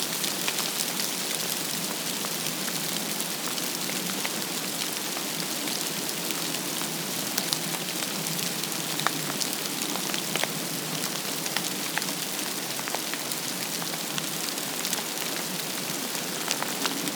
Graupel into beech
Yesterday, on the leading edge of the snow storm, rain turned icy, pelting the woods with interesting nouns-that-should-be-verbs: rime and graupel. This bombardment made for delicious sounds, and not just on the human tongue.
Here is the percussive beat of this snowy ice falling into the marcescent leaves of a young beech (heard best with headphones):